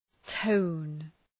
Προφορά
{təʋn}